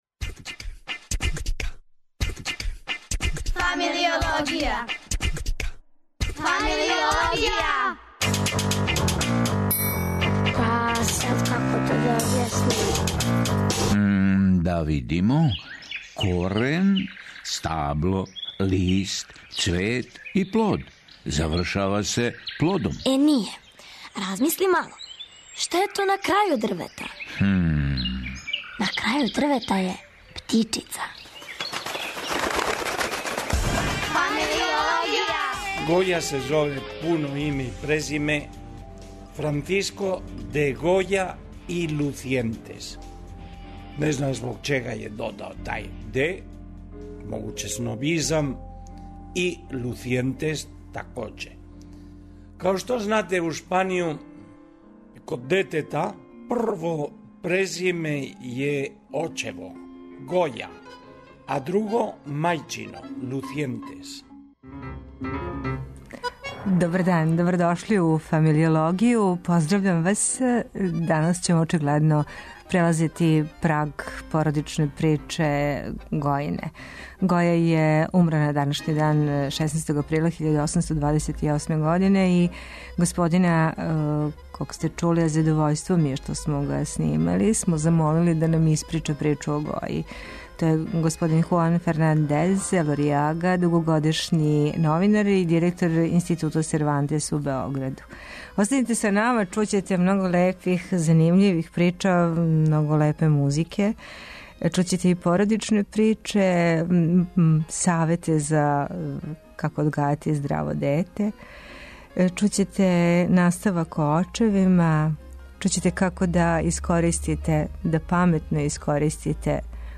У данашњој среди, телефоном, говори о себи...
Радио Београд 1, 13.05